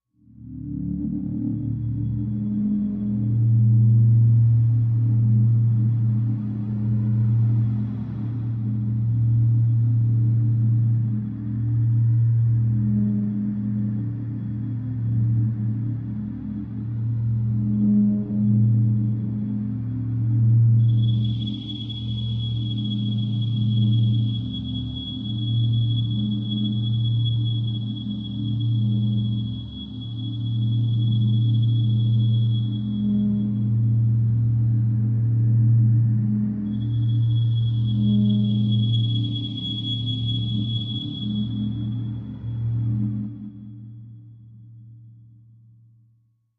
Simple Plan Low Steady Pulse Wind High Tone Halfway Thru